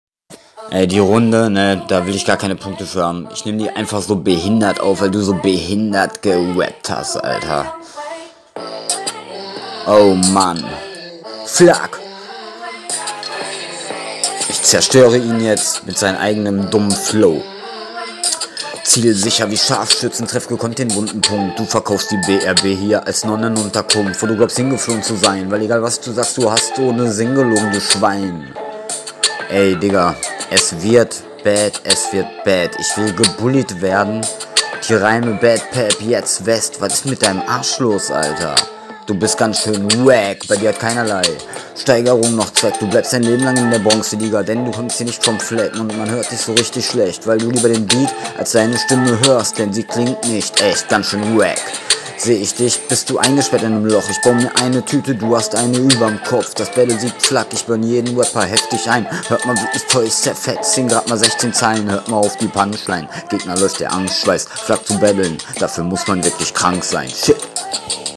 mach ne neue Aufnahme wenn du aus dem Takt kommst.